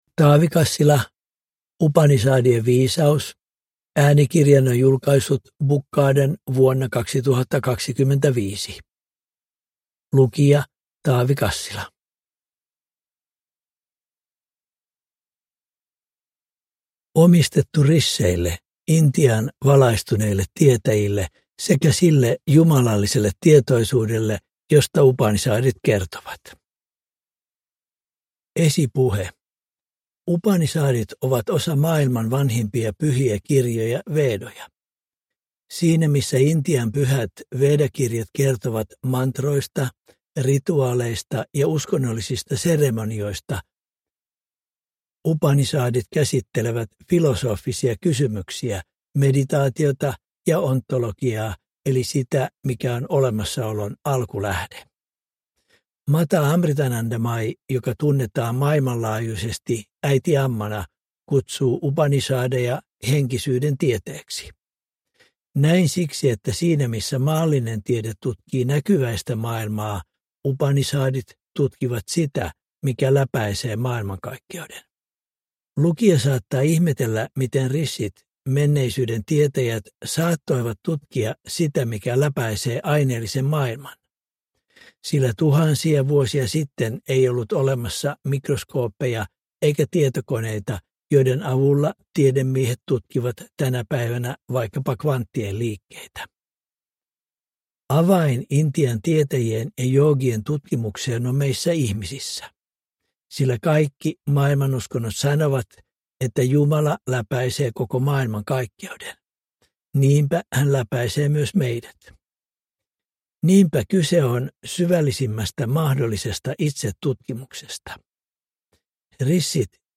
Upanishadien viisaus – Ljudbok